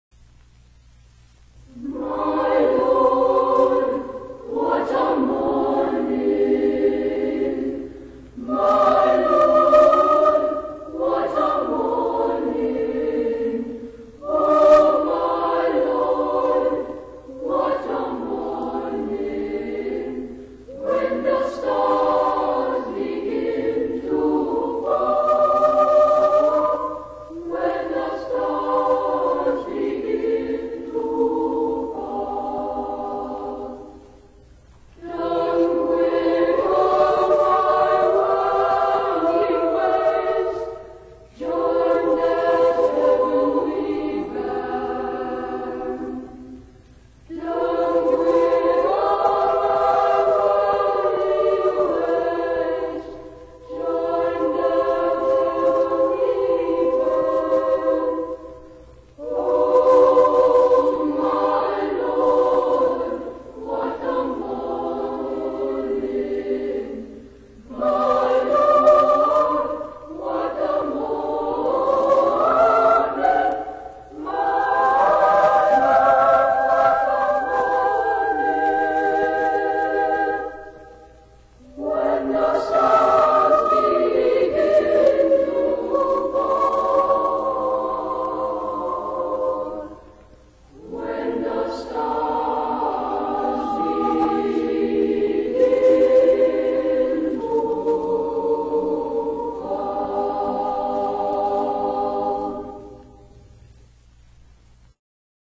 Spiritual